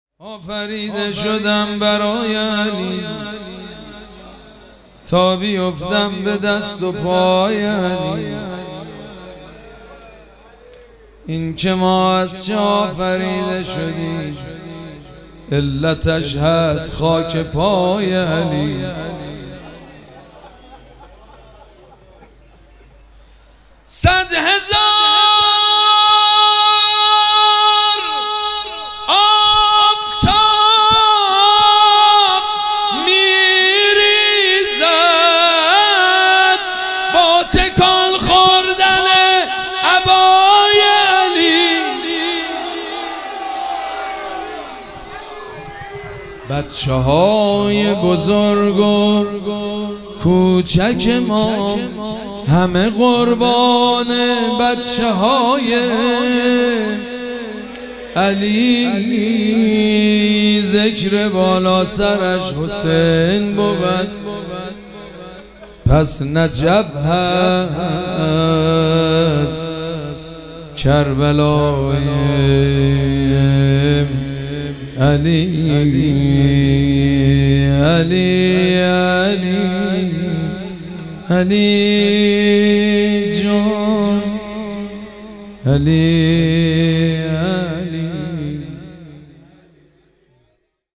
شب پنجم مراسم عزاداری اربعین حسینی ۱۴۴۷
مدح
حاج سید مجید بنی فاطمه